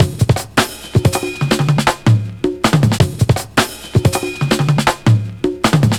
Index of /90_sSampleCDs/Zero-G - Total Drum Bass/Drumloops - 1/track 10 (160bpm)